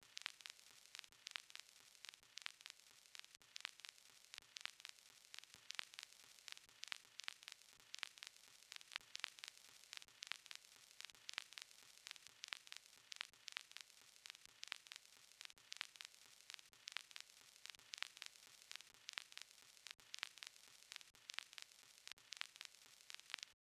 11 vinyl noise.wav